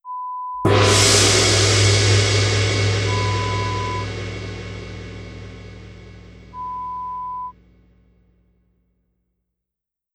Gongo = Gong
Sonido de un gong. Acompaña a dicho sonido un pitido espaciado y constante no relacionado con dicha actividad
instrumento musical
gong